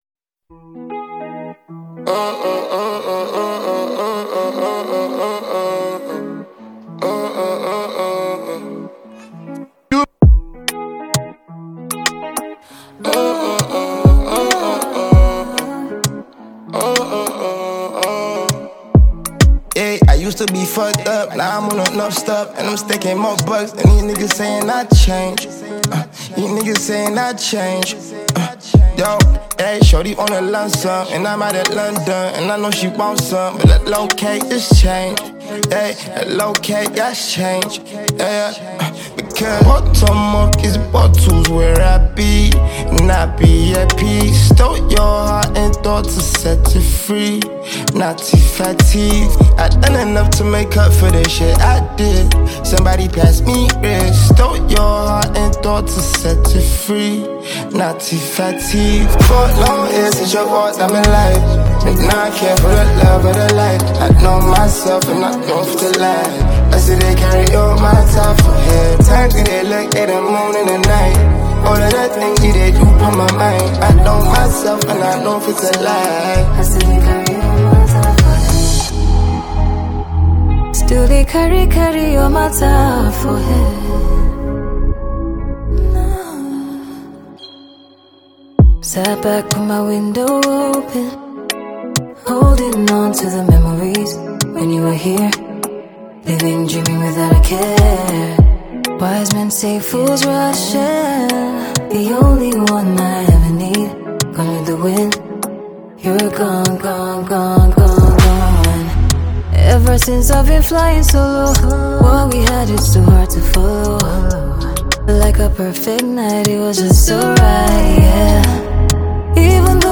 Prominent Nigerian Singer, and Songwriter